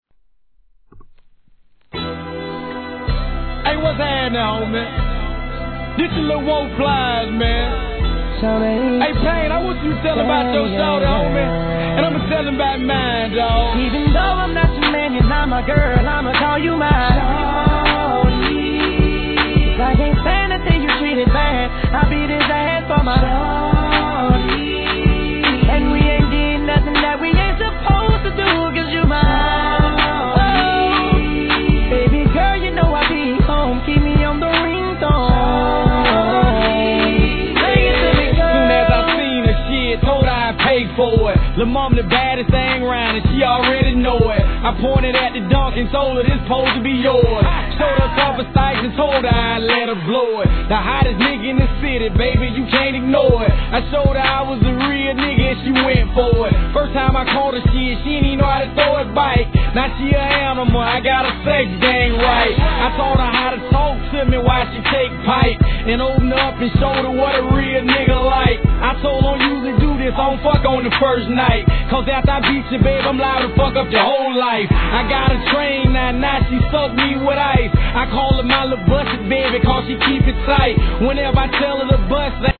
HIP HOP/R&B
指で鳴らしたスネアでビートを刻むイントロ、優しく包み込む様なコーラス、ジワジワと気分が高ぶり、いざドラムビートが入れば